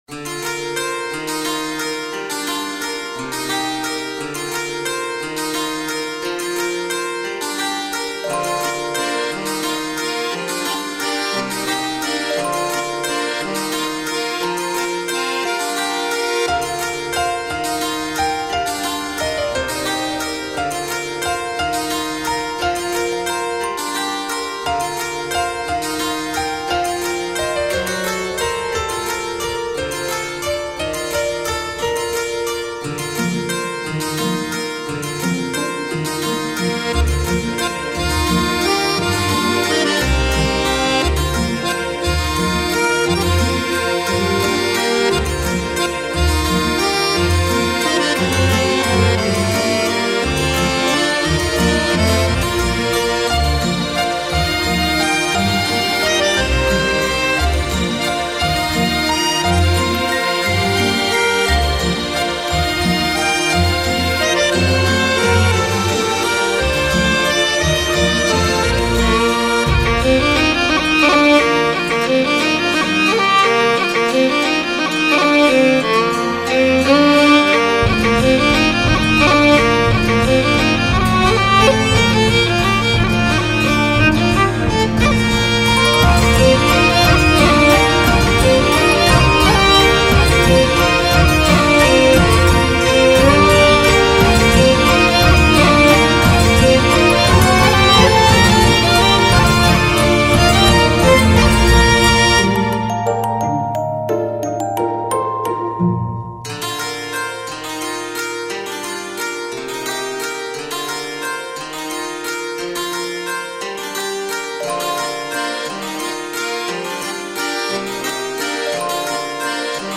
ジャンル室内楽、ワルツ
BPM１７６
使用楽器ダルシマー、アコーディオン、ピアノ、フィドル
解説かわいい雰囲気の室内楽フリーBGMです。
軽快なで愛らしいワルツを目指して制作しました。
ほのぼのした雰囲気のコンテンツ、配信BGM、街村BGMなどにピッタリです。